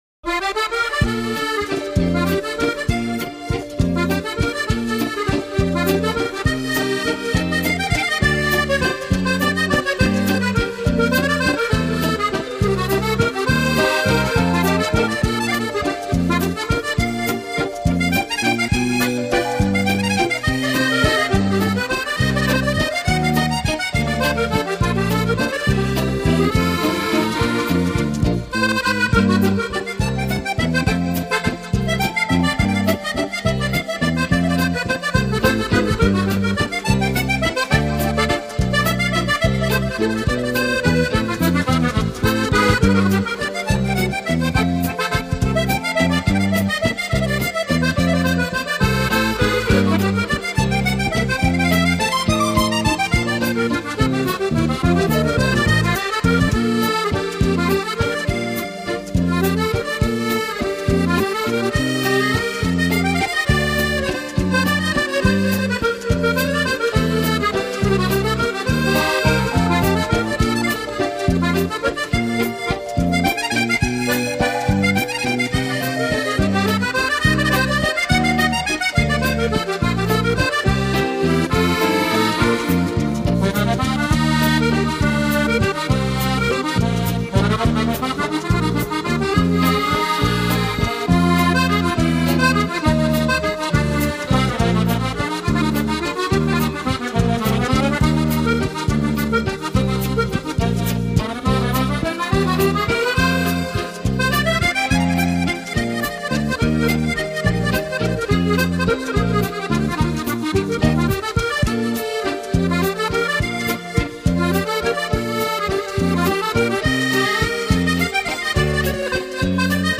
Traditionnel_musette.mp3